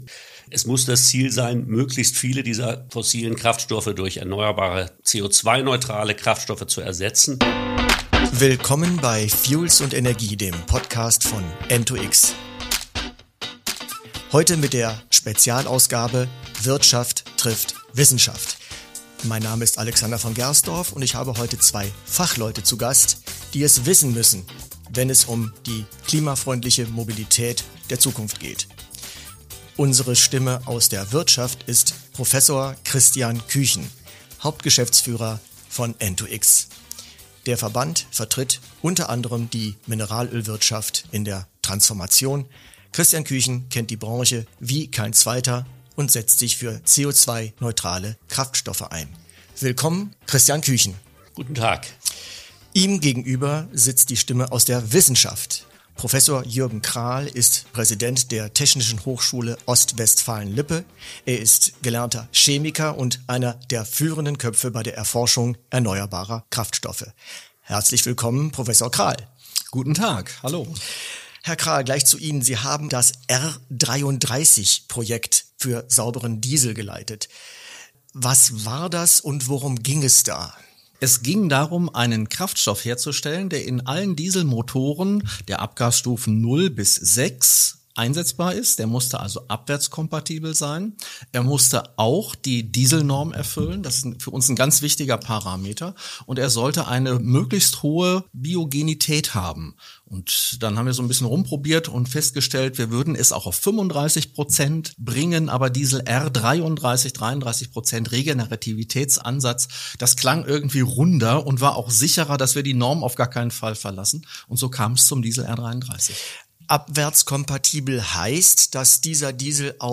mit zwei Experten, die die Praxis und die politischen Rahmenbedingungen der Mobilitätswende bestens kennen